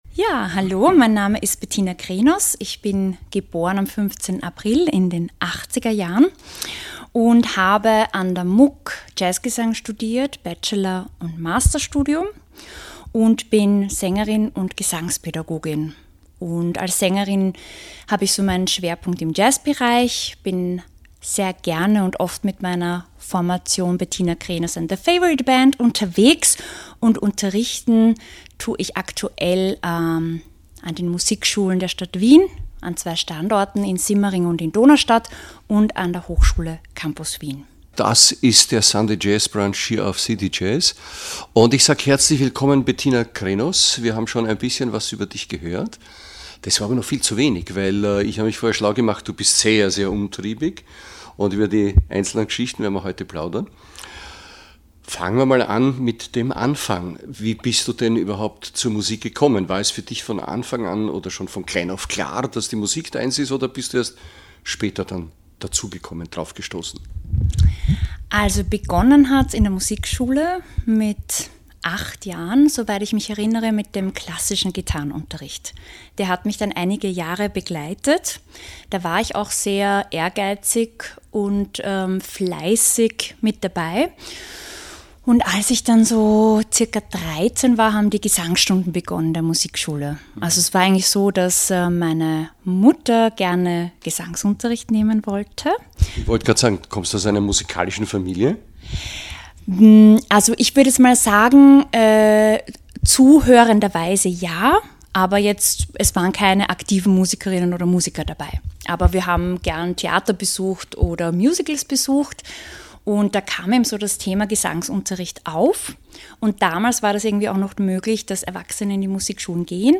Jazz-Musiker, Komponisten und Autoren im Gespräch